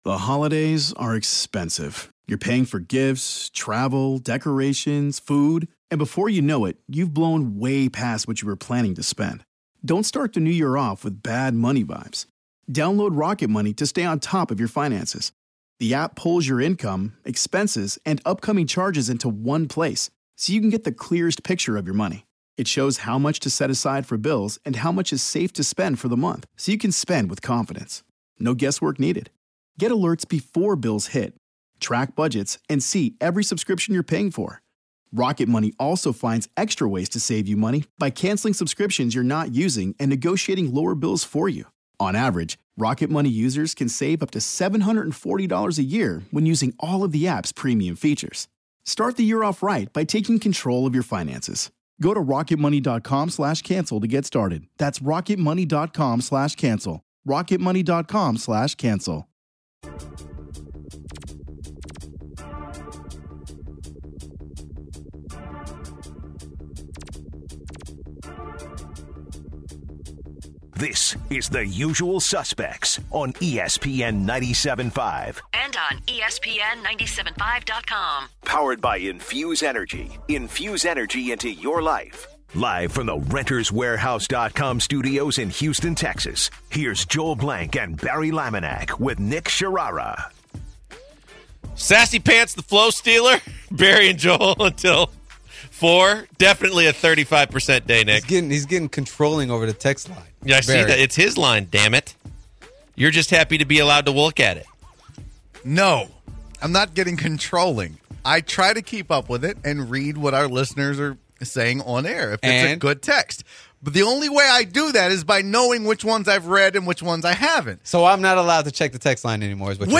On the third hour the guys continue to talk about the Houston Rockets, they also talk about the interns coming into the studio to talk to the hosts.